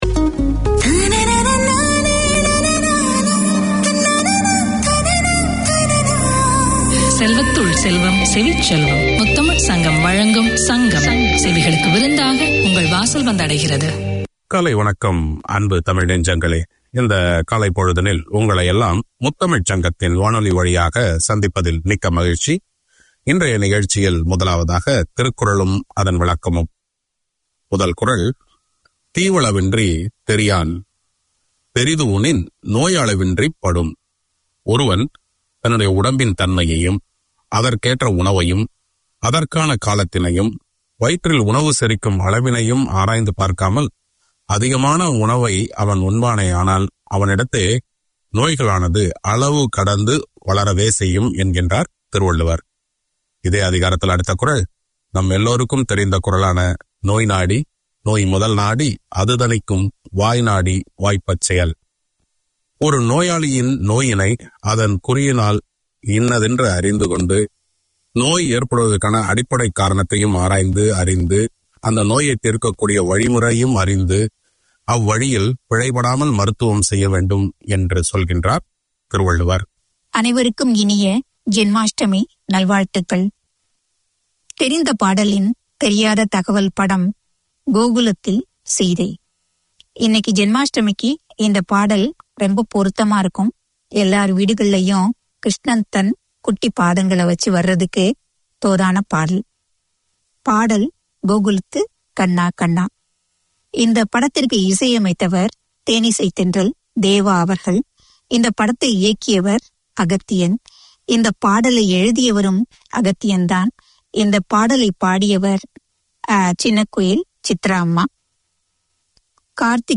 Radio made by over 100 Aucklanders addressing the diverse cultures and interests in 35 languages.
The old and new mix of Tongan music is popular as are the education updates, health information, positive parenting segments, local and Canberra news bulletins. A short devotion opens each 2 hour programme.